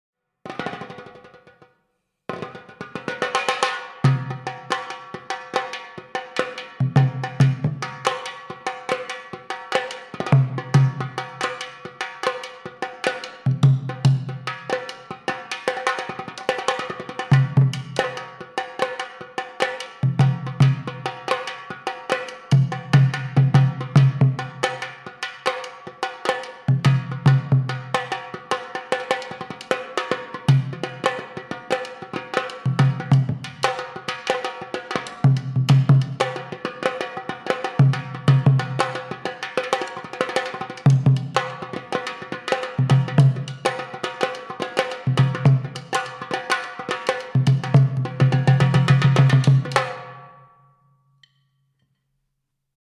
Traditional Moroccan Instrument
Naqqara
Audio file of the Naqqara